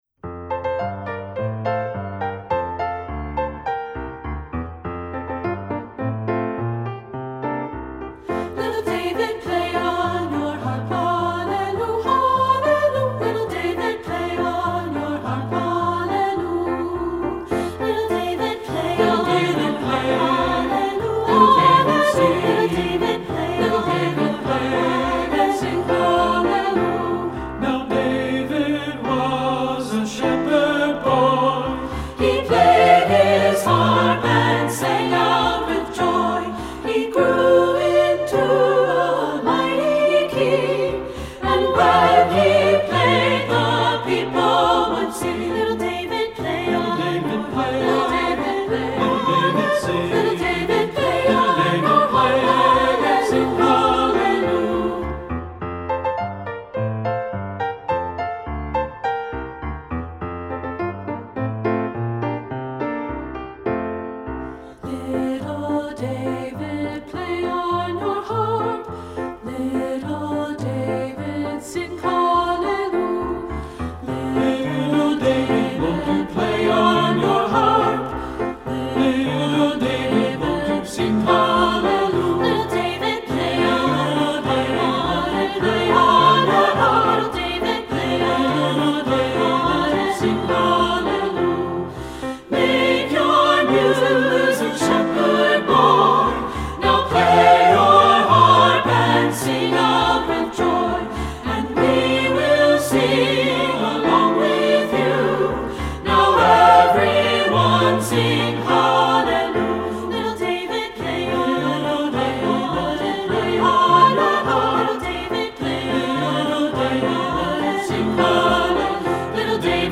- Three-part Mixed
Voicing: Three-part